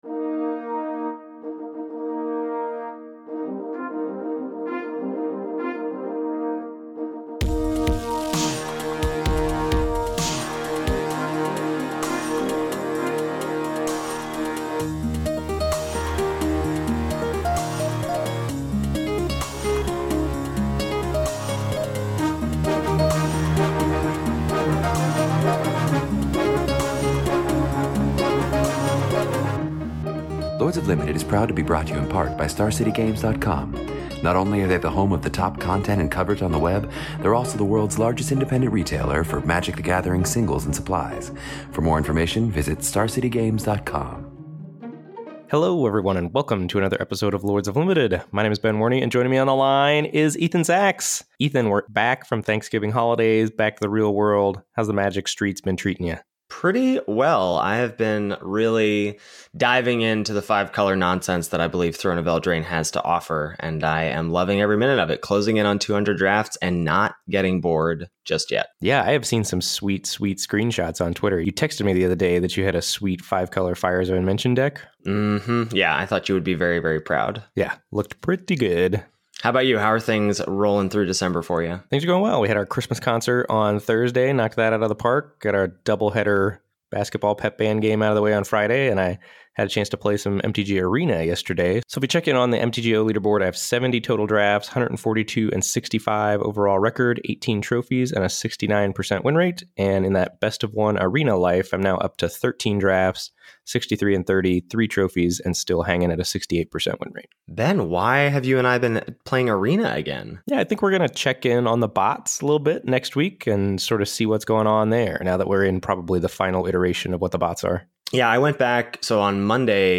pisode is 128 is here packed to the brim with more discussions of in-game decision making. This time around your hosts are talking "Keep or Mull" with a variety of opening hands and Sideboarding with some Throne of Eldraine specifics.